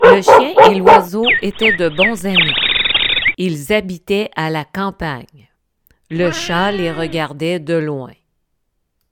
texte_simple_sonorise_.mp3